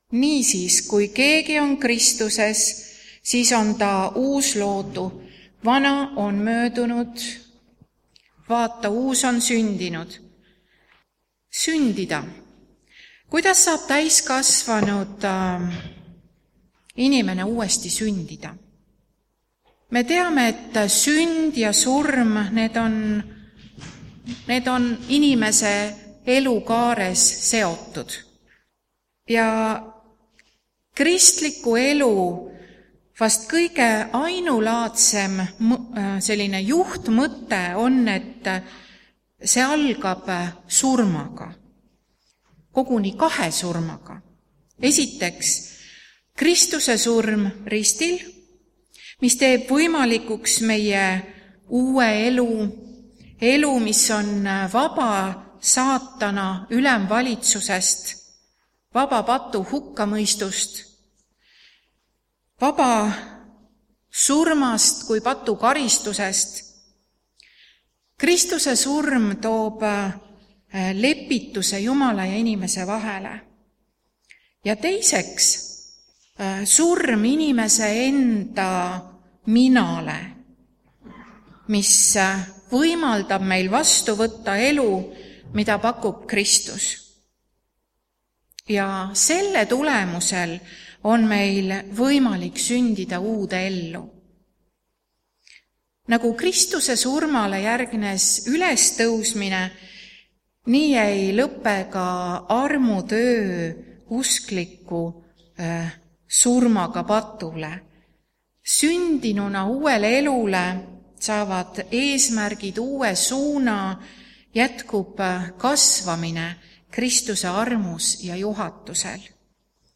Uue aasta esimene jutlus ja esimene ristimine.